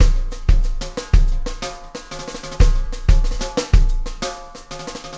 Extra Terrestrial Beat 08.wav